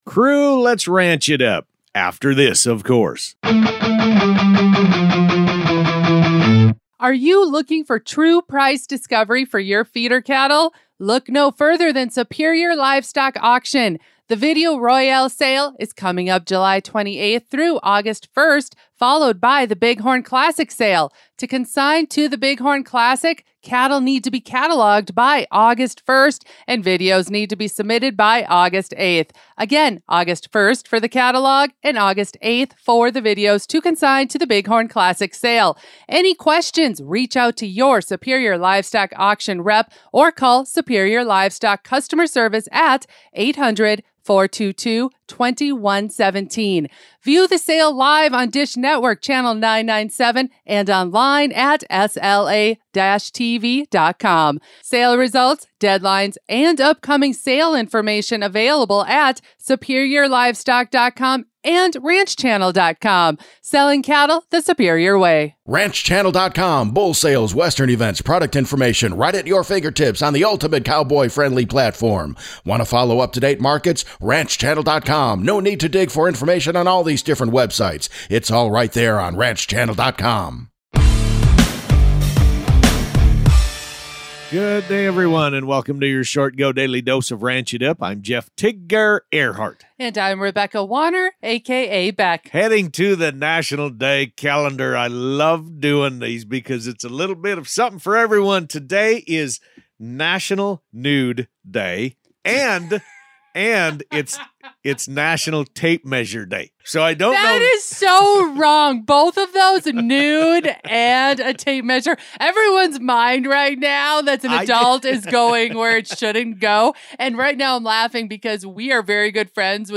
They're serving up the most important ranch-related headlines, from new flavor drops and condiment controversies to the best pairings and fan favorites. Expect insightful (and hilarious) commentary, listener shout-outs, and everything you need to stay in the loop on all things ranch.